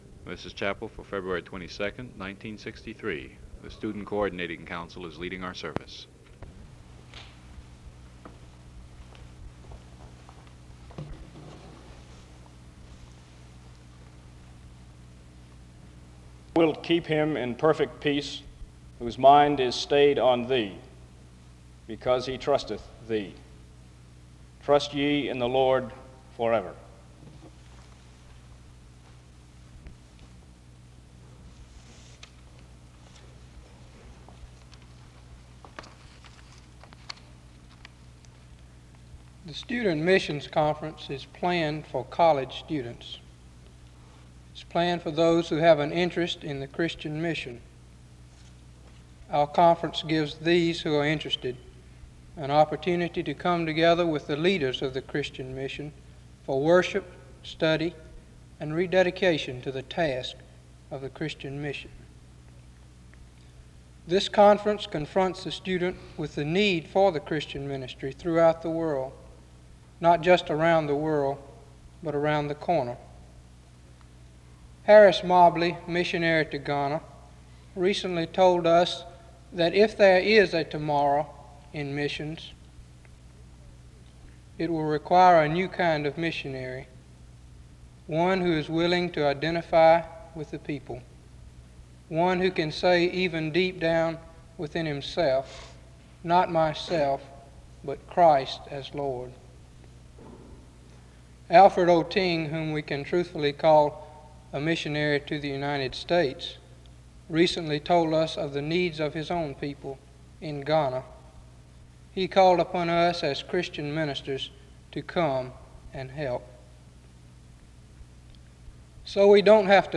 Download .mp3 Description The service begins with an introduction to the student mission's conference from 0:16-3:36. A student gives an announcement about an opportunity to serve at the developmentally challenged hospital from 3:37-4:25. A word of prayer is made from 4:30-5:32. Another student shares a message about the extension committee from 5:41-12:12.
A final student closes by noting the importance of discussion groups from 25:15-35:36.